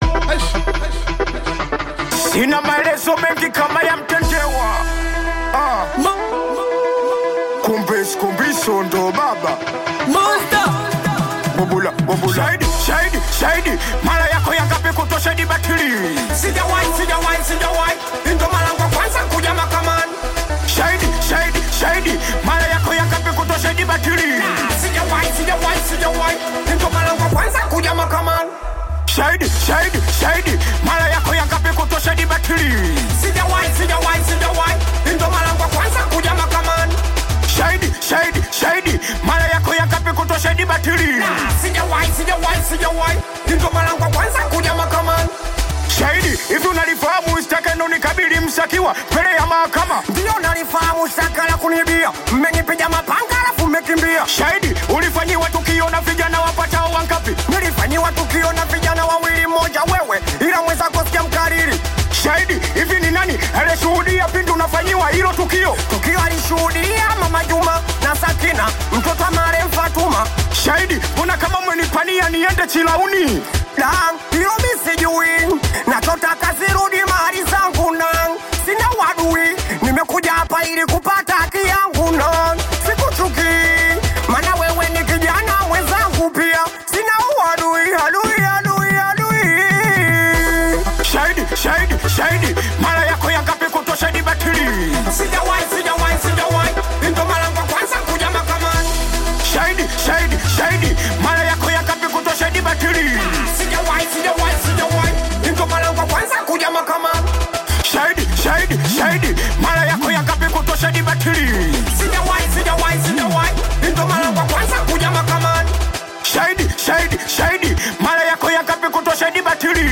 contemporary world music